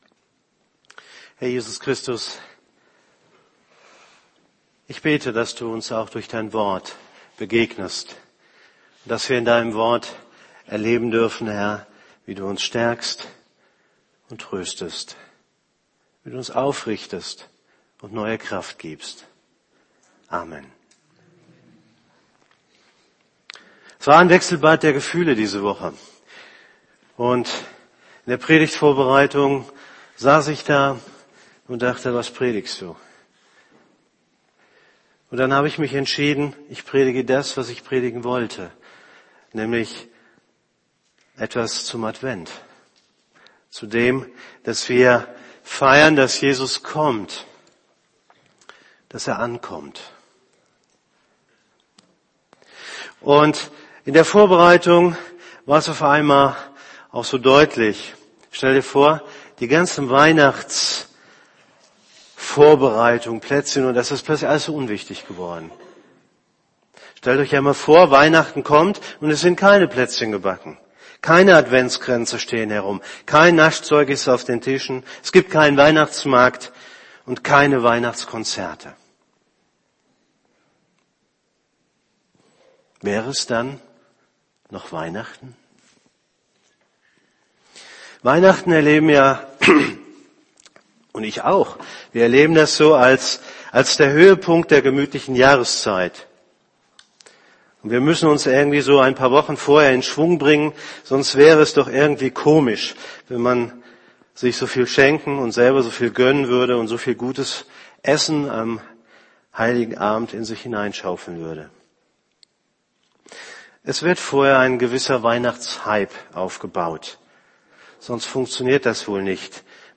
Predigt vom 01. Dezember 2013 Predigt Predigttext: Lukas 2,25-38 Matth�us 24,27 Lukas 17,23-24 Offenbarung 22,16-17 Apg 4,12
predigt.mp3